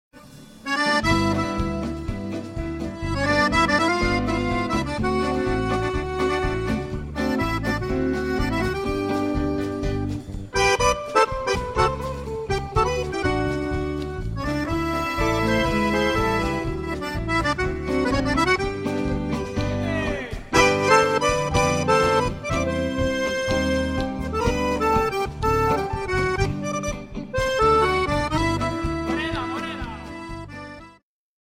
paso